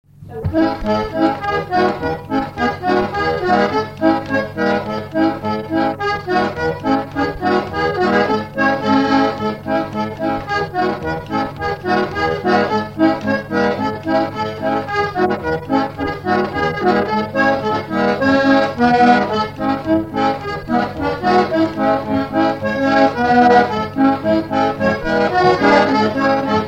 Polka
Instrumental
danse : polka
Pièce musicale inédite